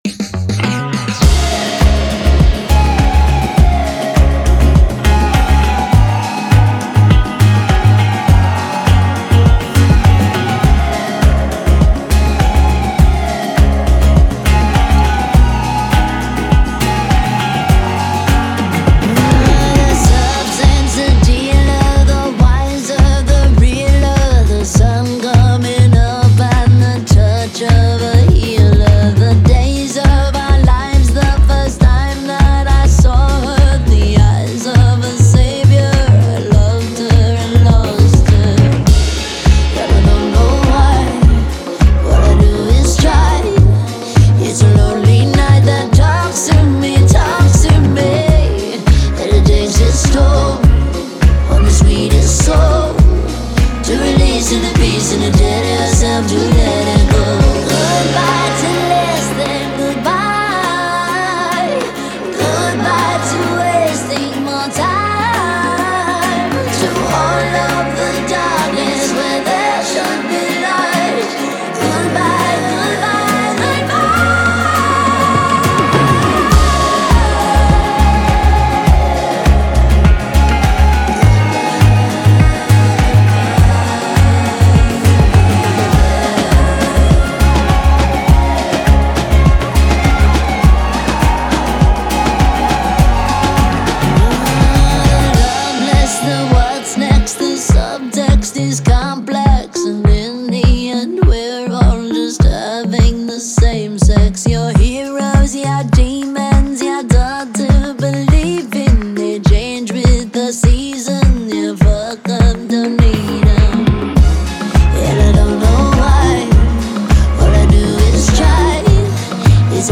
Звучание песни выделяется мощным вокалом